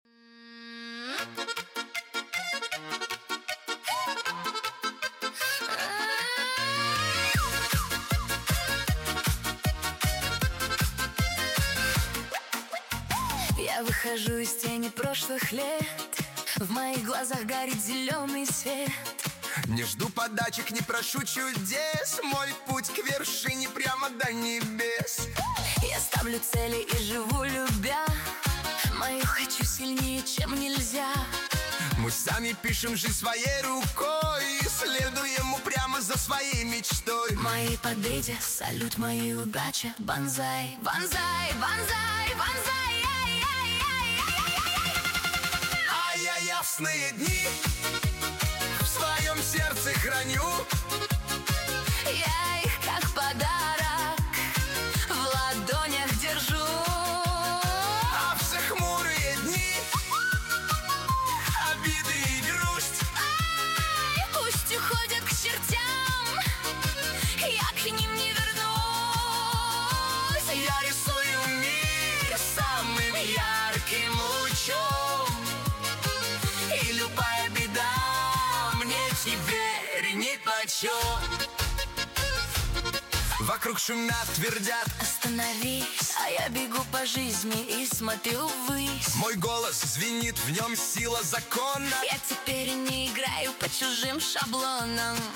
Нейросеть Песни 2025, 2026